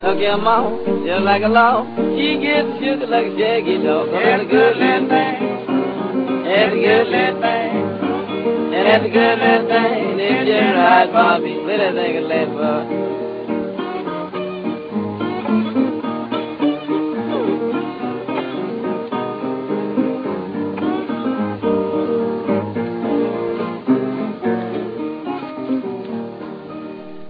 ведущий вокал, гитара